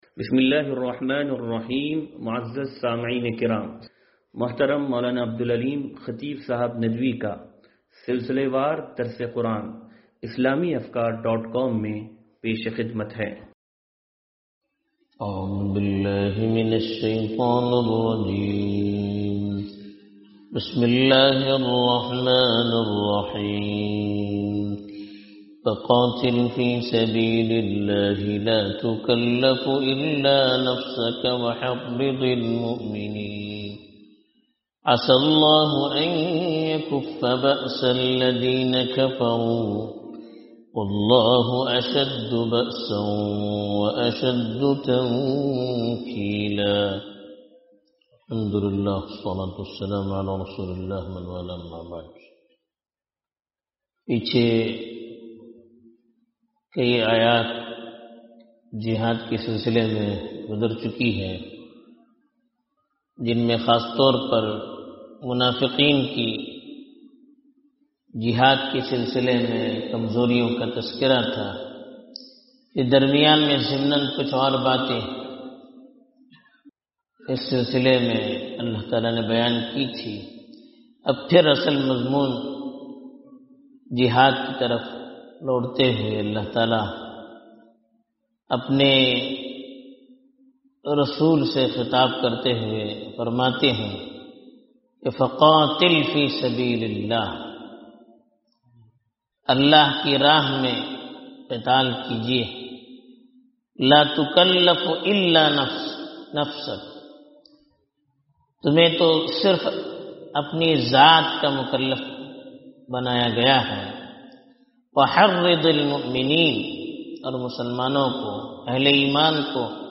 درس قرآن نمبر 0373